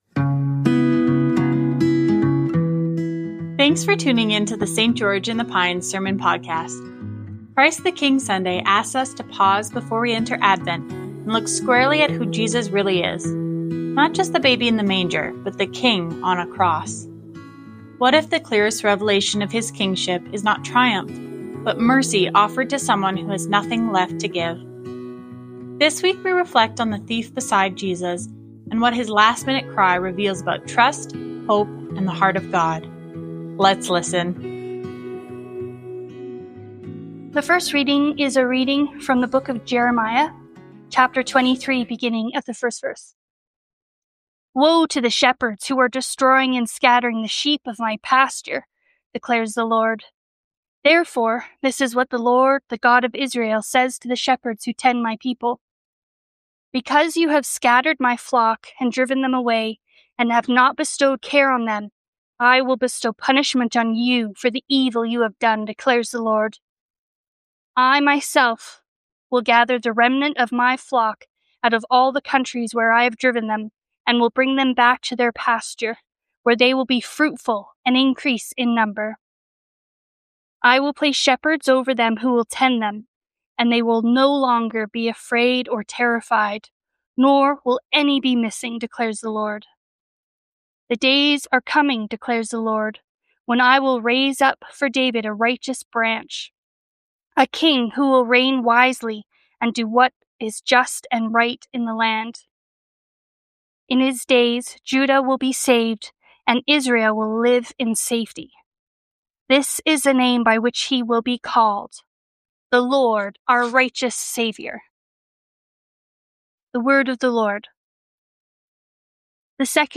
Whole Life Generosity Current Sermon KING We are saved not by what we bring but by Who we behold.